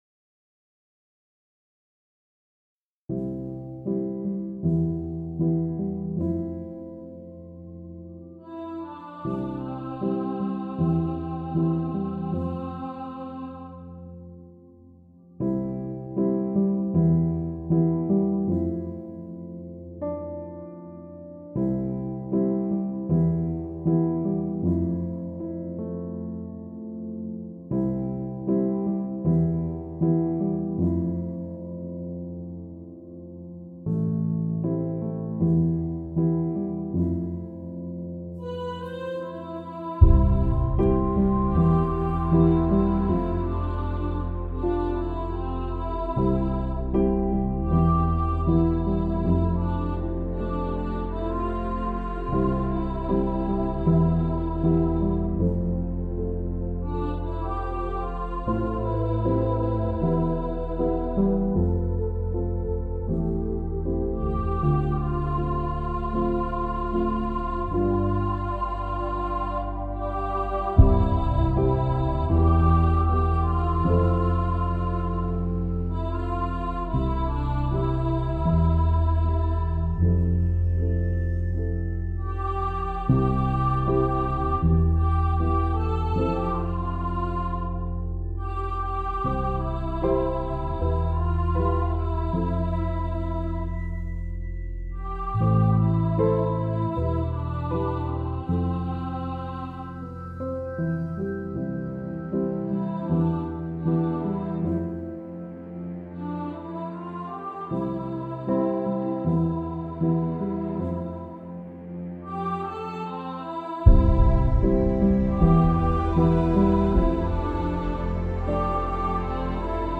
What Was I Made For – Alto | Ipswich Hospital Community Choir